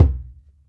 SingleHit_QAS10777.WAV